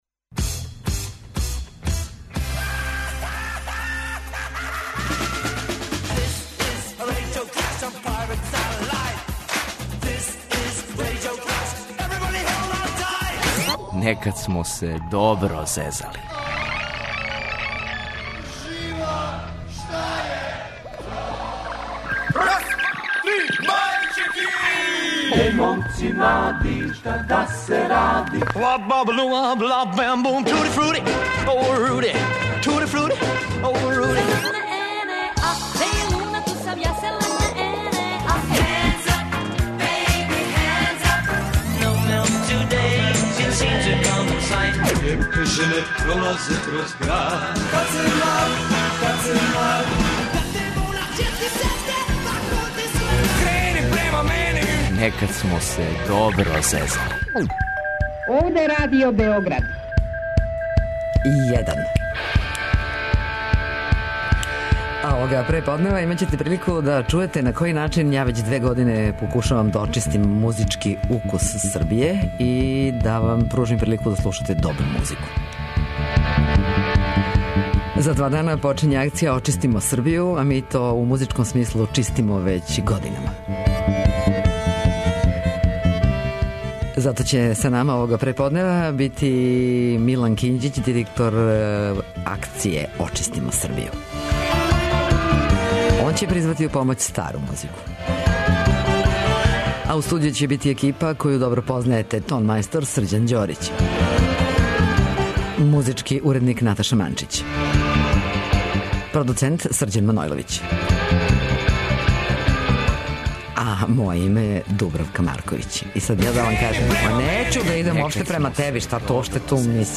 Вратиће нас музиком у време када је стасавала генерација рођена седамдесетих година, а можда и овако успе да прочисти звук у Србији.